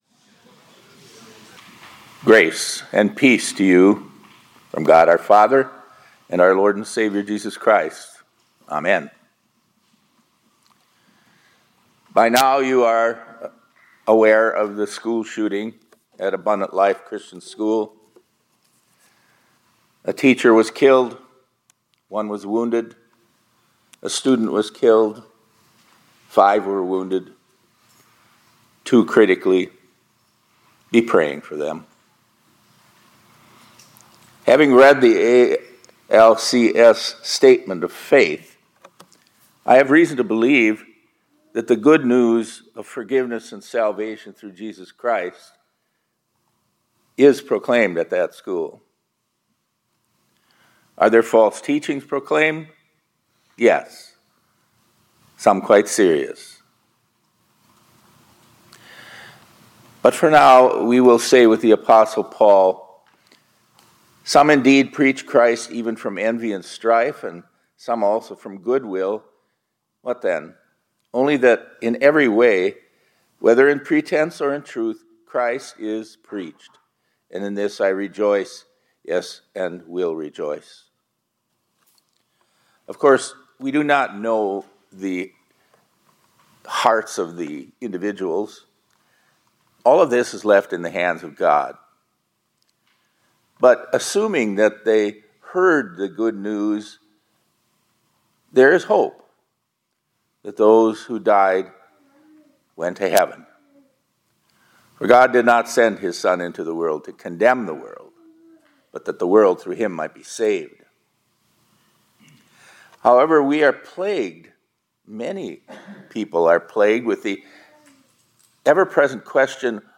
2024-12-18 ILC Chapel — The Christchild is Proof of…